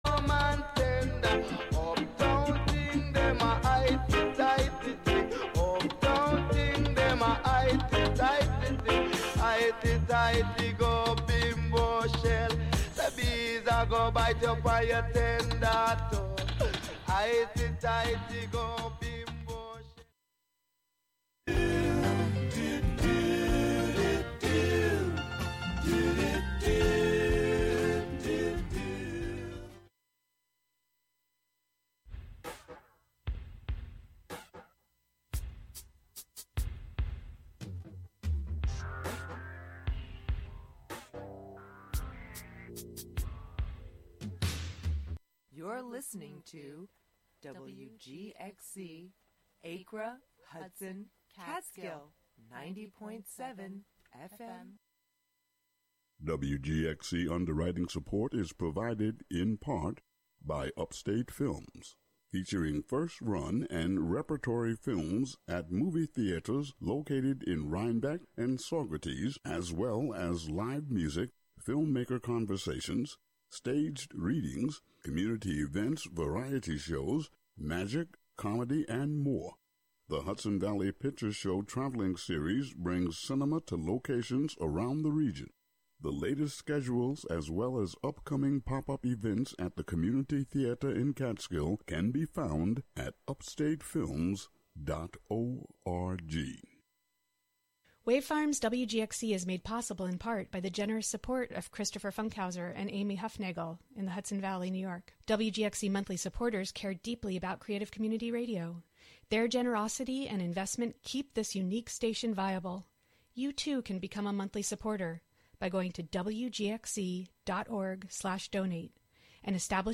Monthly excursions into music, soundscape, audio document, and spoken word, inspired by the wide world of performance. This month we consider the Constitution. Live from Ulster County.